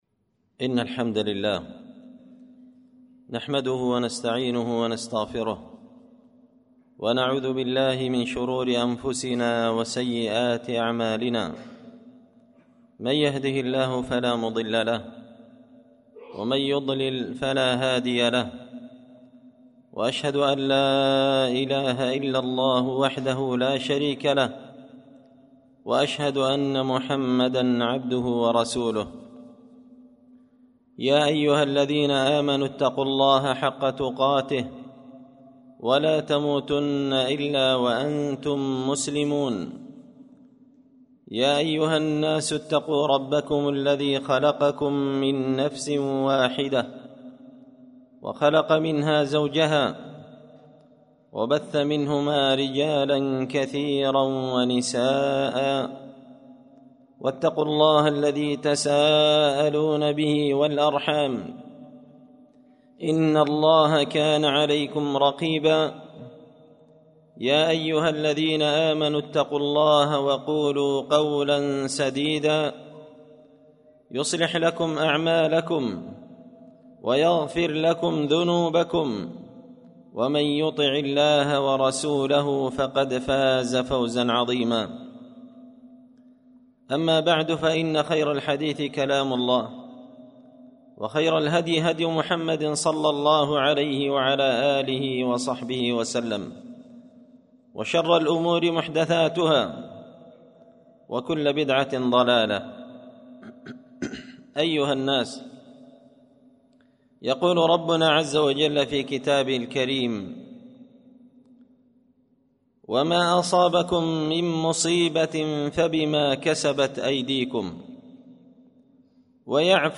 خطبة جمعة بعنوان – المرحمة ببيان الحيل المحرمة
دار الحديث بمسجد الفرقان ـ قشن ـ المهرة ـ اليمن